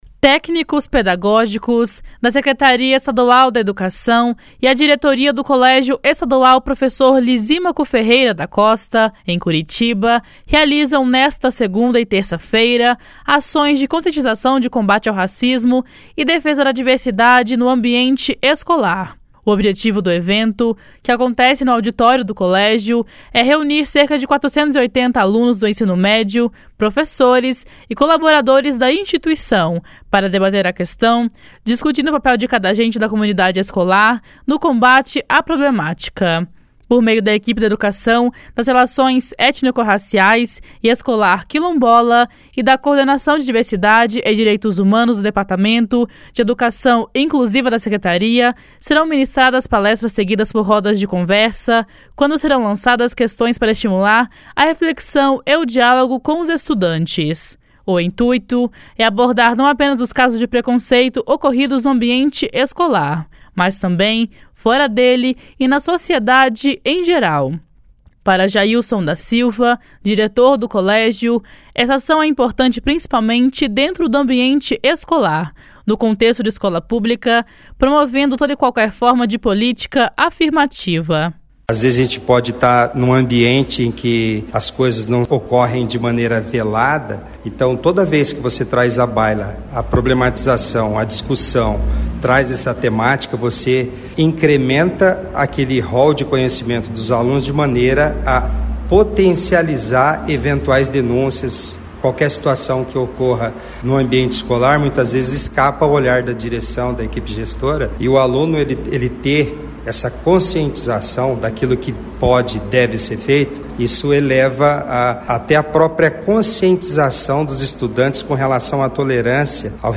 O evento vai abordar o papel de cada indivíduo no processo de denúncia e na prevenção destas situações. (Repórter